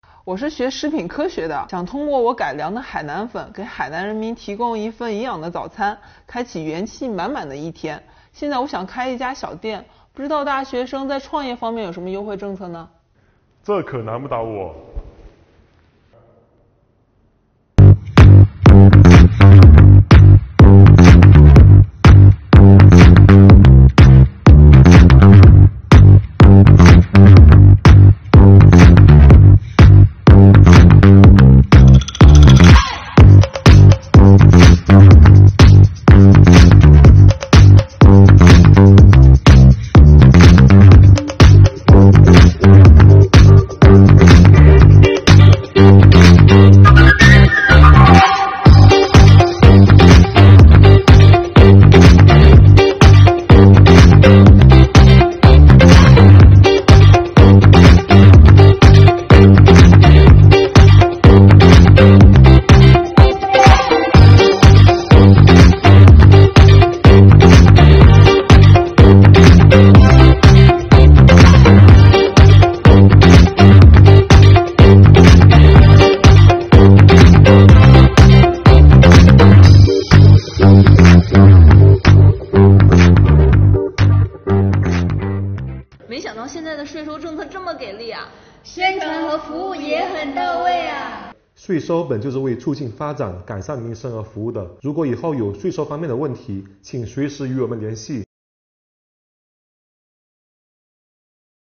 背景音乐轻快，与画面完美地交融在一起，让人眼前一亮。